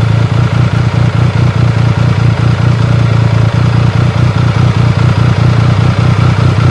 motor.ogg